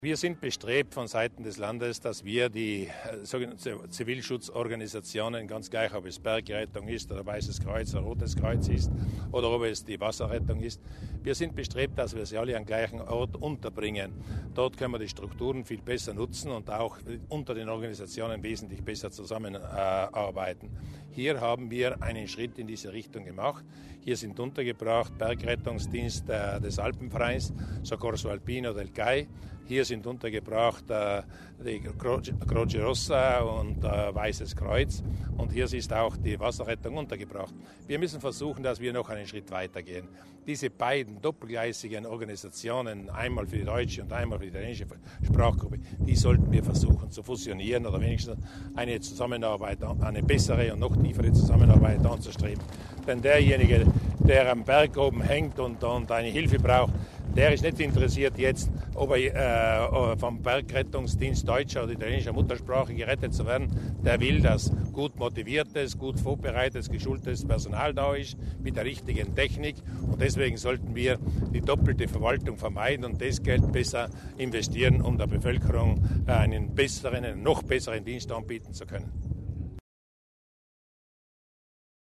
Landesrat Mussner über die Wichtigkeit der Unterstützung der Freiwilligenarbeit